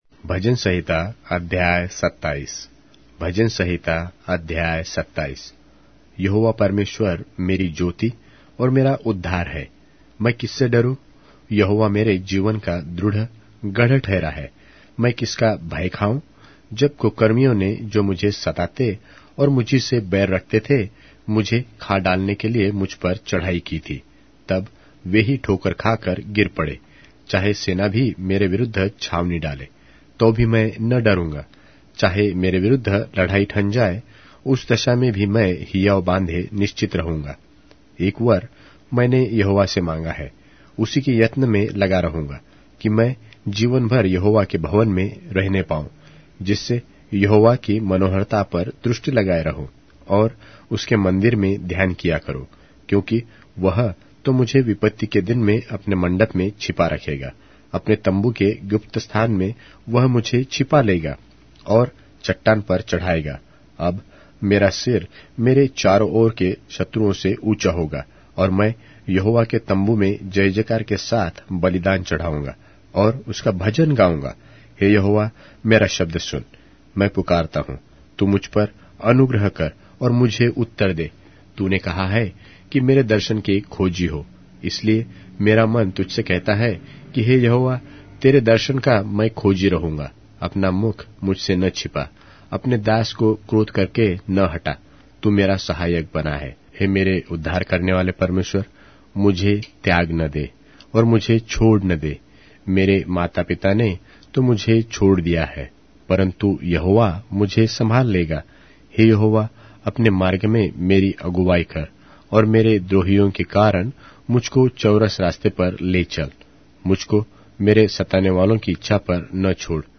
Hindi Audio Bible - Psalms 6 in Irvgu bible version